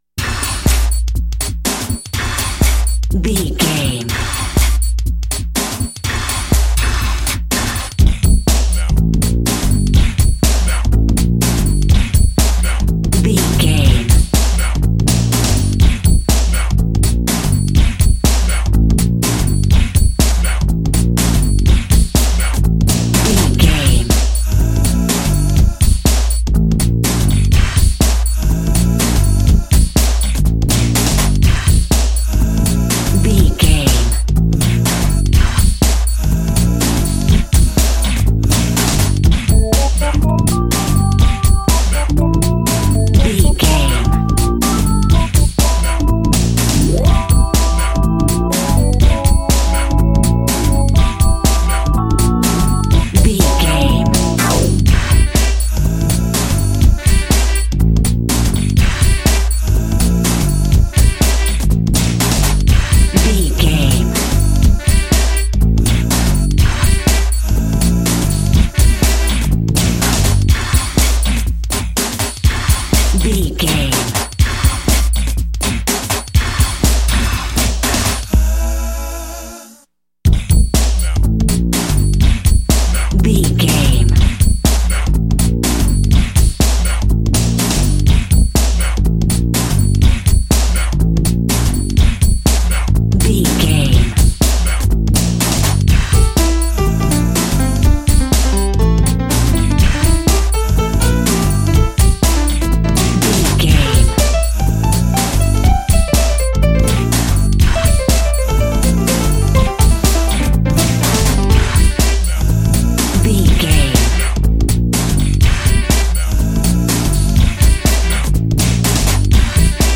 Ionian/Major
synthesiser
drum machine
electric guitar
drums
strings
90s
Eurodance